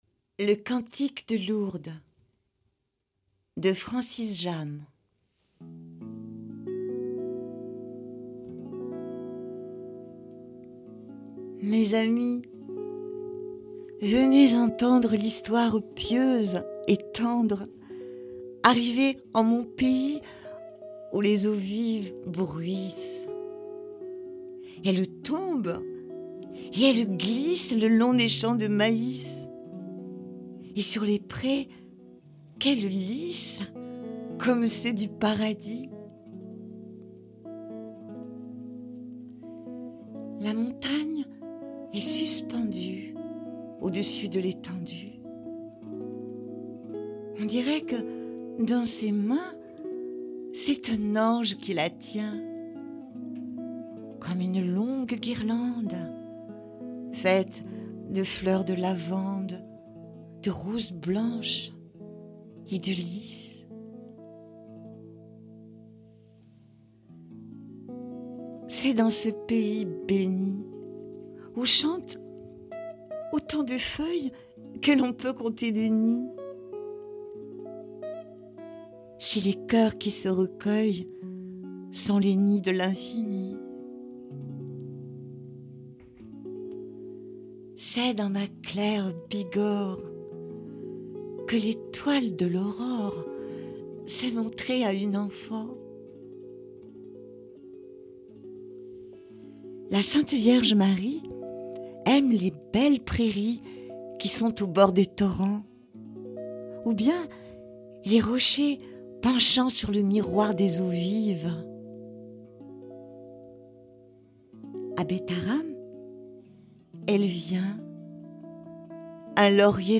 Merci pour cette interprétation sensible et inspirée !